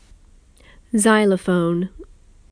発音に注意してください。